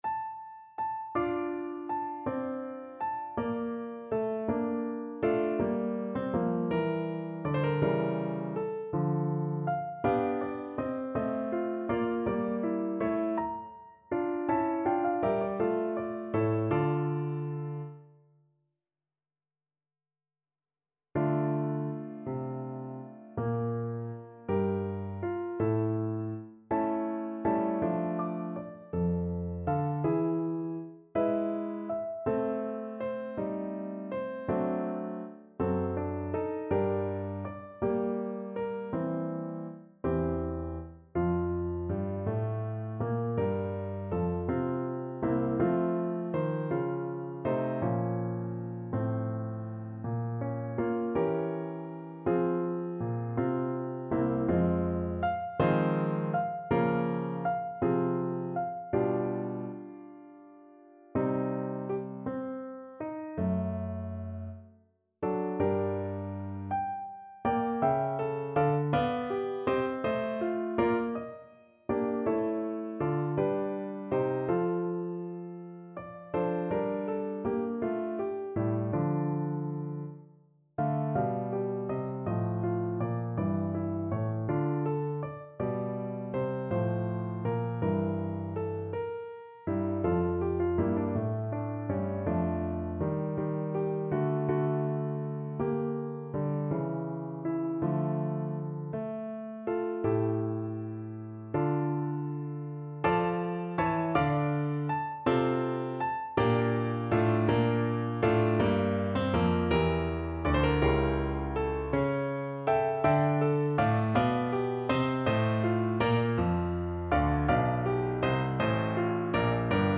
Play (or use space bar on your keyboard) Pause Music Playalong - Piano Accompaniment Playalong Band Accompaniment not yet available reset tempo print settings full screen
12/8 (View more 12/8 Music)
D minor (Sounding Pitch) (View more D minor Music for Tenor Voice )
~. = 54 Larghetto
Classical (View more Classical Tenor Voice Music)